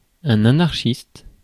Ääntäminen
Synonyymit libertaire autogestionnaire anarchisant Ääntäminen France: IPA: [a.naʁ.ʃist] Haettu sana löytyi näillä lähdekielillä: ranska Käännös Substantiivit 1. анархистка Suku: f .